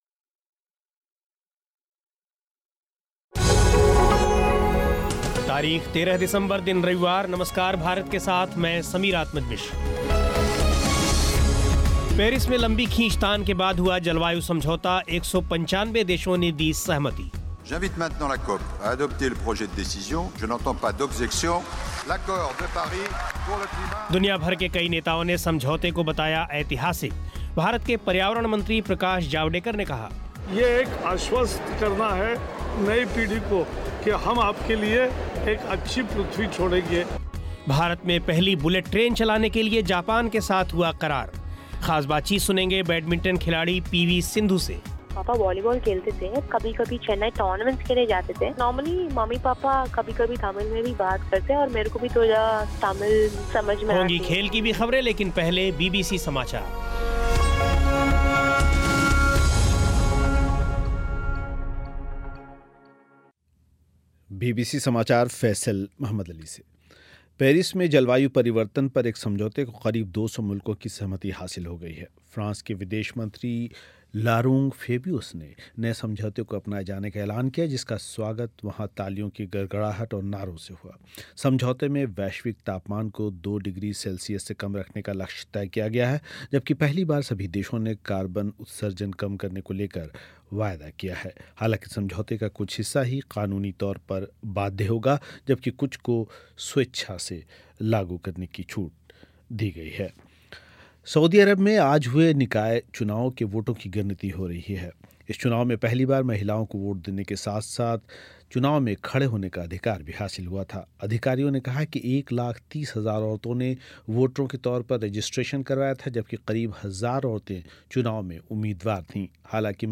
भारत में पहली बुलेट ट्रेन चलाने के लिए जापान के साथ हुआ क़रार, ख़ास बातचीत सुनेंगे बैडमिंटन खिलाड़ी पीवी सिंधु से....... होंगी खेल की भी ख़बरें.